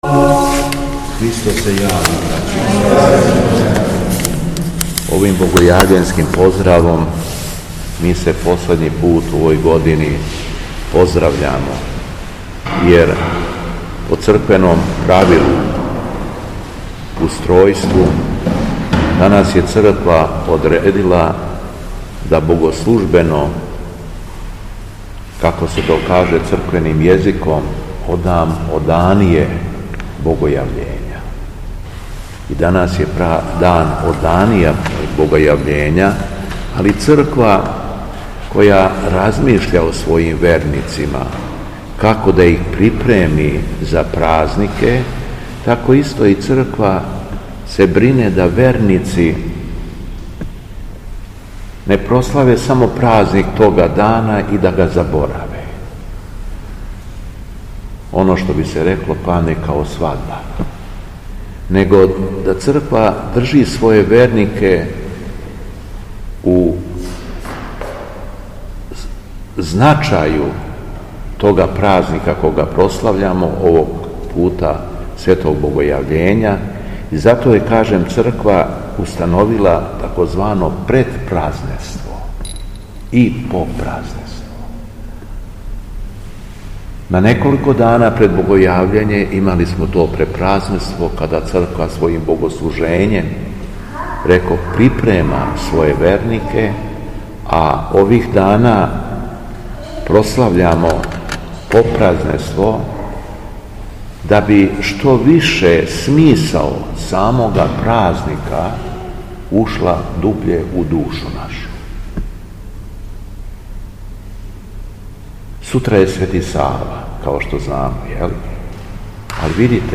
У петак, 26. јануара 2024. године, на Оданије Богојављења, Његово Преосвештенство Епископ шумадијски г. Јован служио је свету архијерејску Литургију у храму Свете Петке у крагујевачком насељу Виногради уз саслужење братства овога светога храма. Звучни запис беседе - Господ све чини не ради себе већ да би показао шта ми треба у животу да чинимо да би се спасили, а Бог даје себе да би се људи спасили.